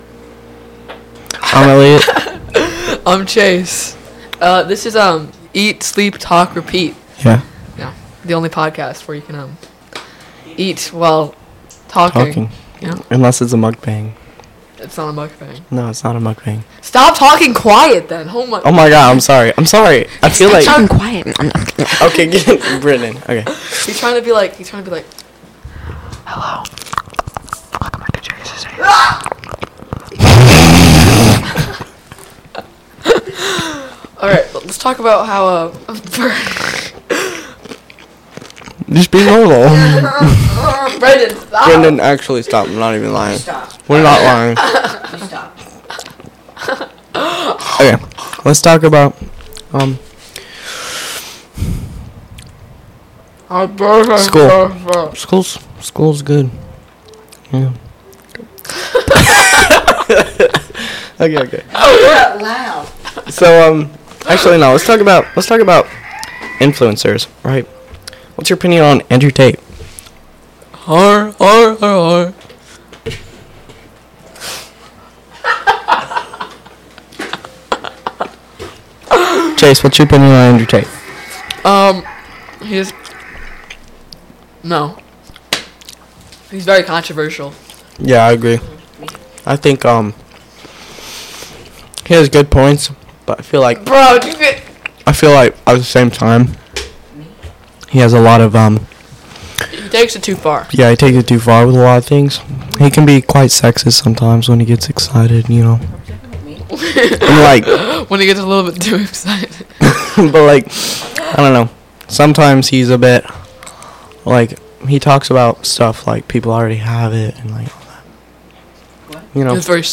The only podcast where two guys talk about random crap and food while doing it.